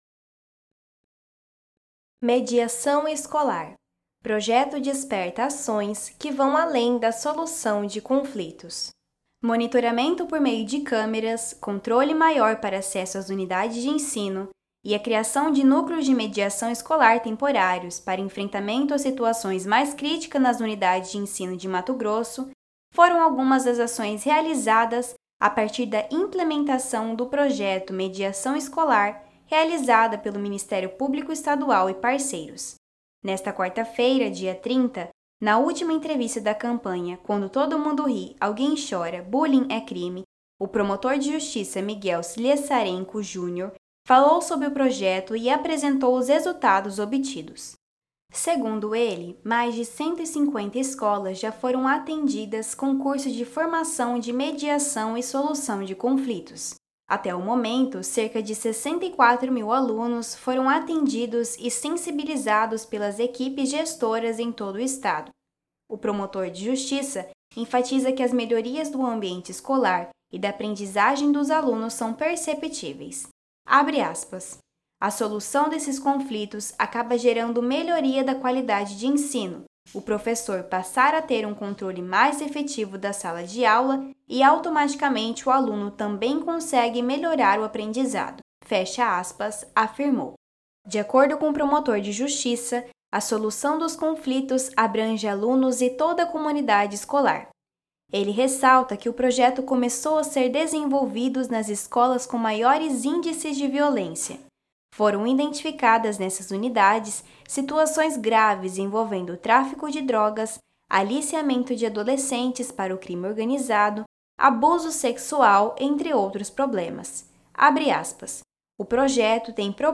Campanha – Durante todo o mês de outubro, como parte da campanha “Quando todo mundo ri, alguém chora. Bullying é Crime!”, foram realizadas várias entrevistas sobre a temática na Rádio CBN Cuiabá.